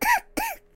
project_files/HedgewarsMobile/Audio/Sounds/voices/Default_uk/PoisonCough.ogg
PoisonCough.ogg